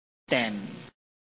臺灣客語拼音學習網-客語聽讀拼-海陸腔-鼻尾韻
拼音查詢：【海陸腔】dem ~請點選不同聲調拼音聽聽看!(例字漢字部分屬參考性質)